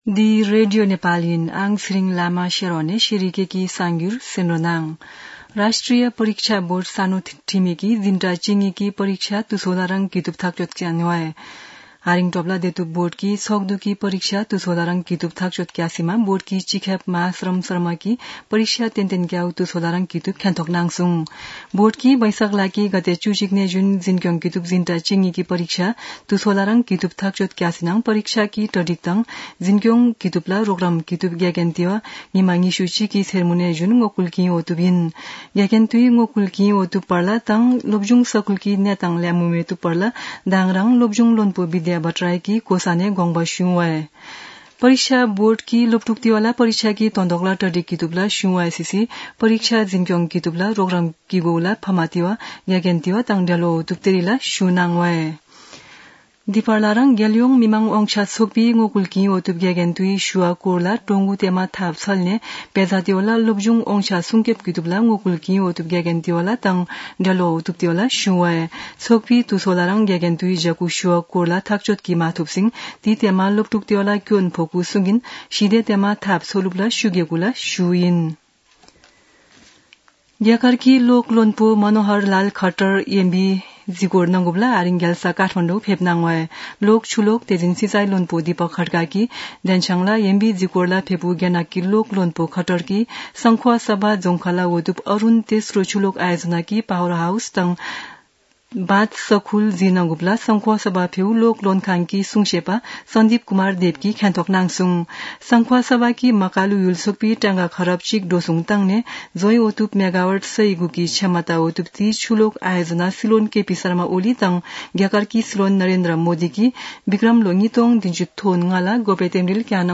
शेर्पा भाषाको समाचार : ९ वैशाख , २०८२
sharpa-news.mp3